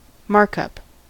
markup: Wikimedia Commons US English Pronunciations
En-us-markup.WAV